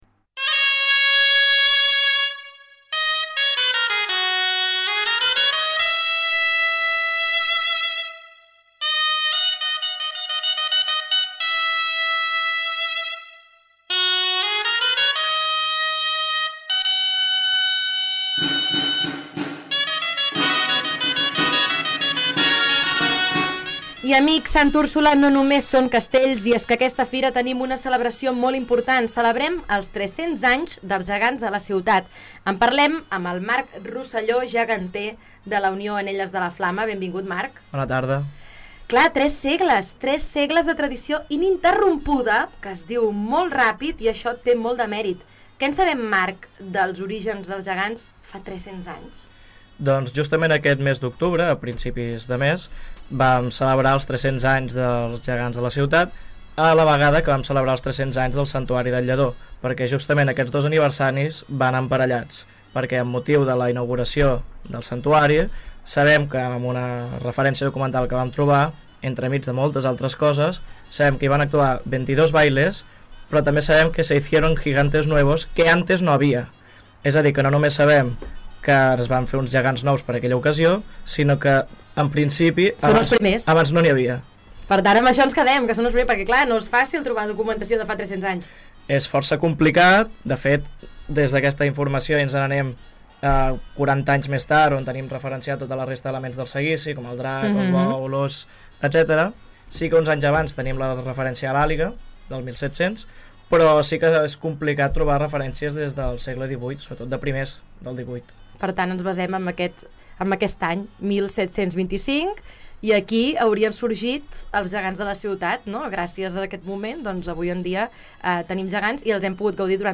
Nova entrevista a Ràdio Ciutat de Valls. Valls celebra enguany els 300 anys de la primera referència documental dels Gegants de la Ciutat, uns dels elements més estimats i emblemàtics del patrimoni festiu vallenc.